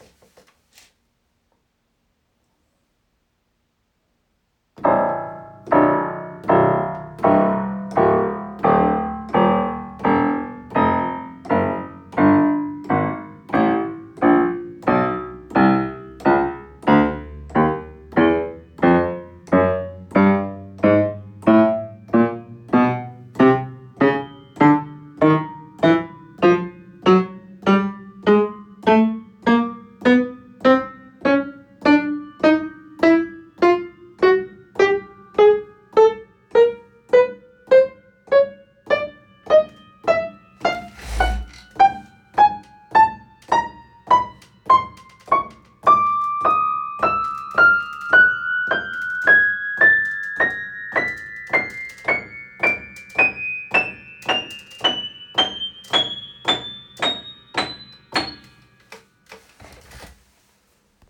Das Klavier schaut ziemlich neuwertig aus, jedoch irgendwie ist mir gestern aufgefallen das die Bass-Töne irgendwie dumpf klingen.